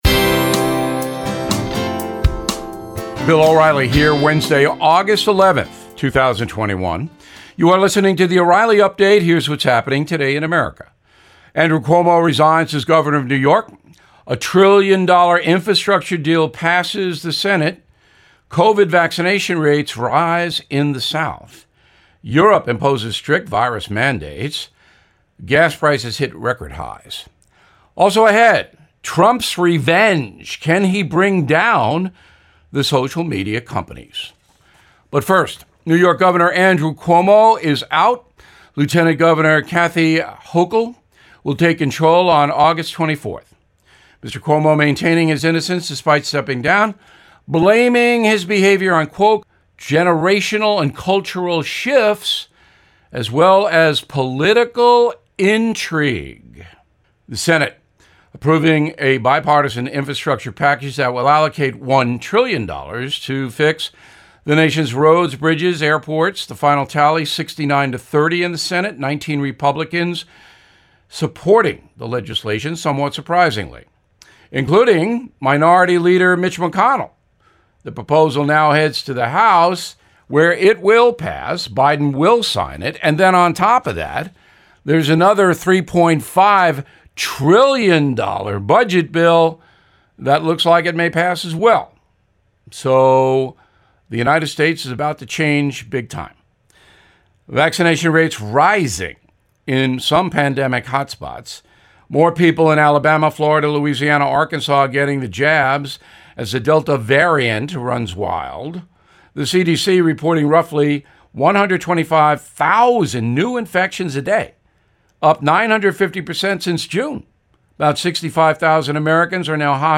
That’s why Bill O’Reilly is back with his daily radio feature, The O’Reilly Update.